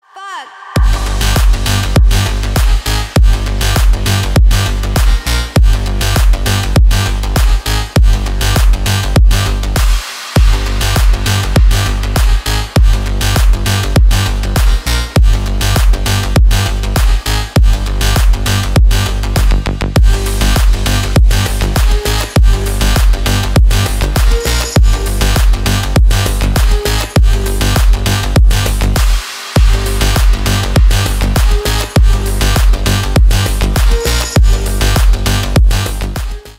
Танцевальные
клубные # громкие # без слов